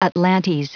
Prononciation du mot atlantes en anglais (fichier audio)
Prononciation du mot : atlantes